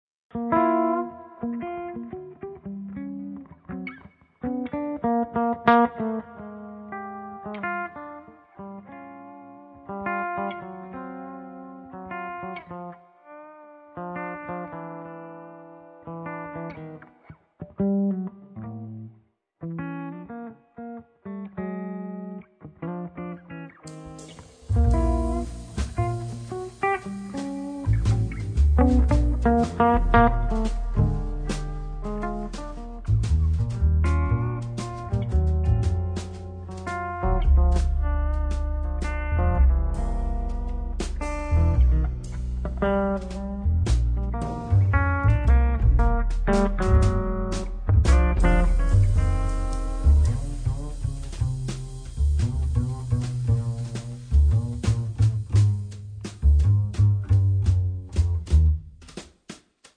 chitarra
pianoforte
contrabbasso
batteria
Il suono del gruppo risulta compatto
un tema blues